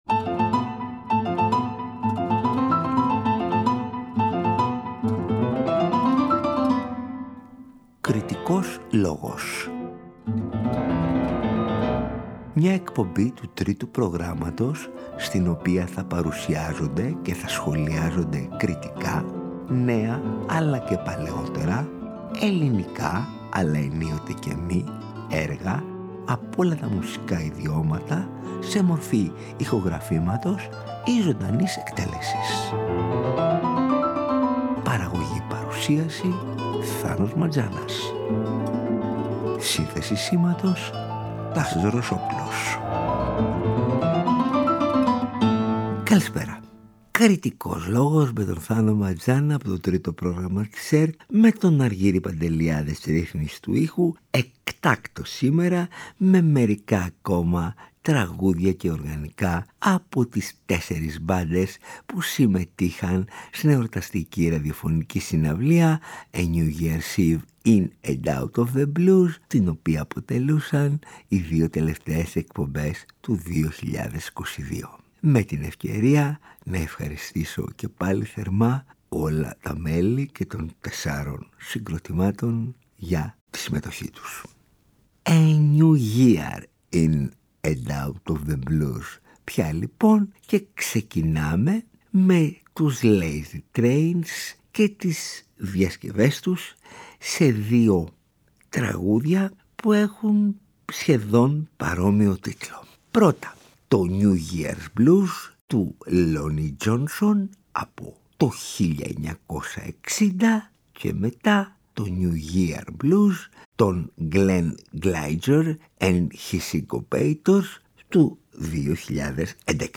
blues κουαρτέτο
organ trio